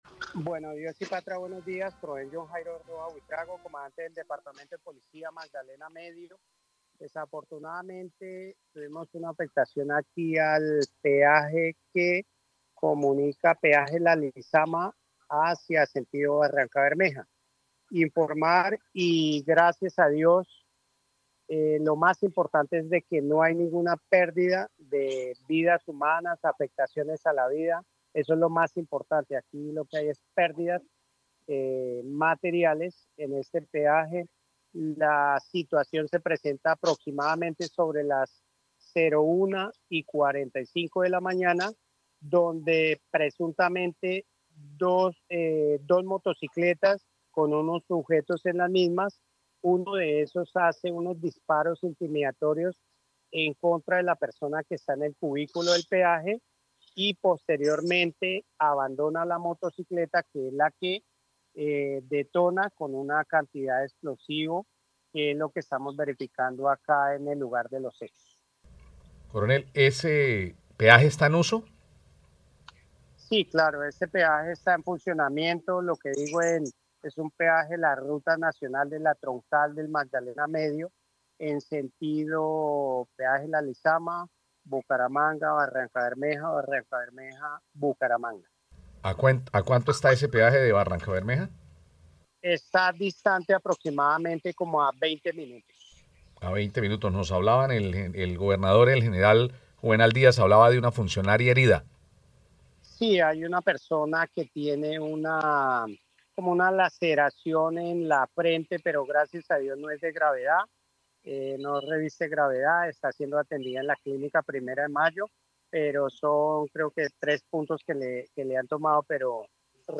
Coronel John Jairo Roa, comandante departamento de Policía Magdalena Medio